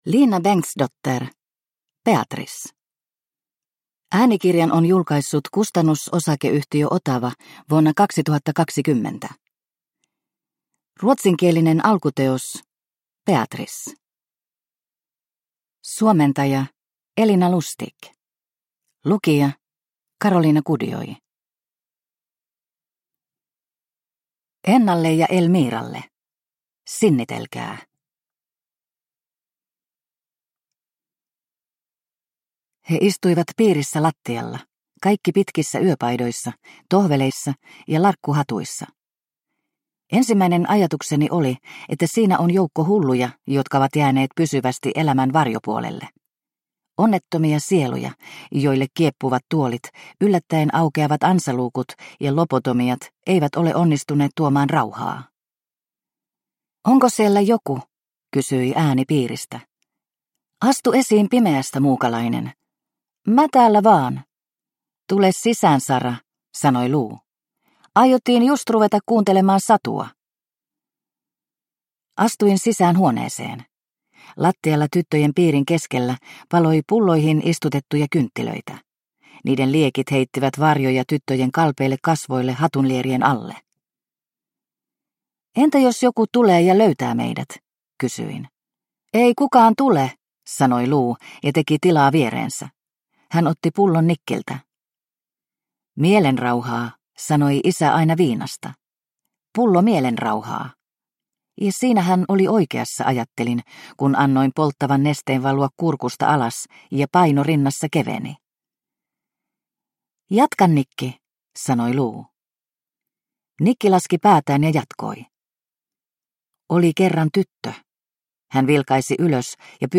Beatrice – Ljudbok – Laddas ner